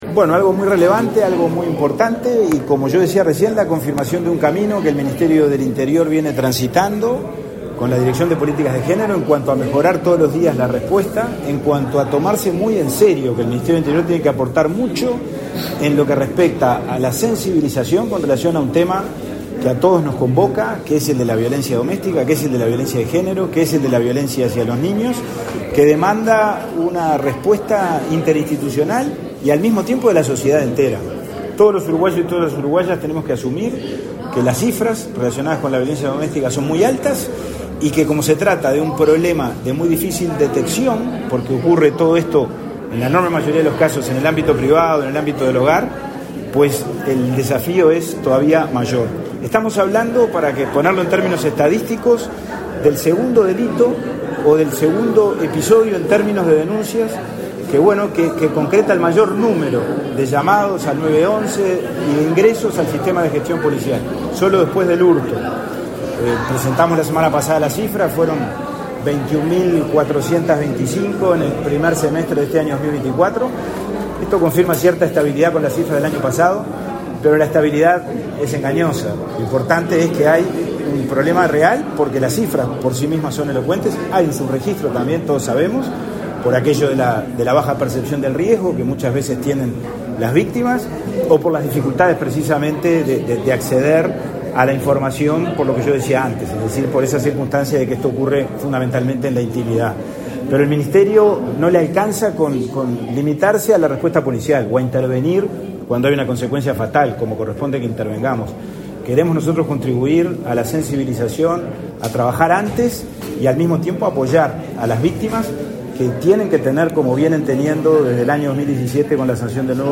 Declaraciones a la prensa del subsecretario del Ministerio del Interior, Pablo Abdala
Tras participar en la inauguración de la dependencia de la sede de la Dirección Nacional de Políticas de Género, en el marco de su cuarto aniversario,